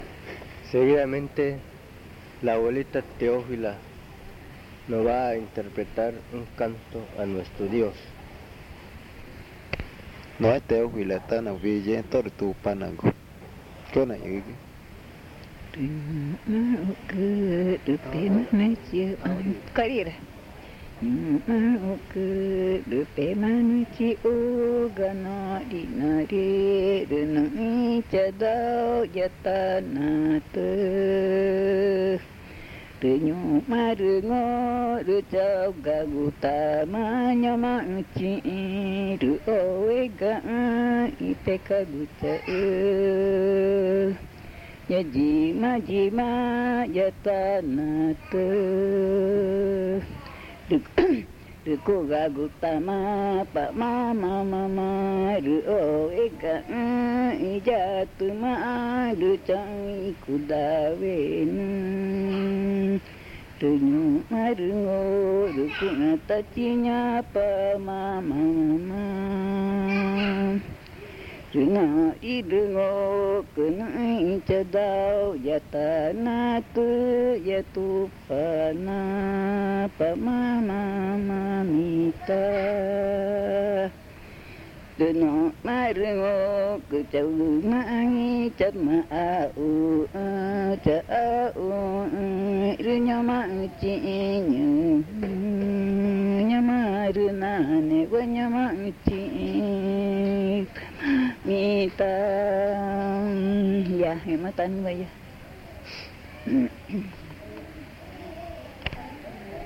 Canto a nuestro dios Yoi
Pozo Redondo, Amazonas (Colombia)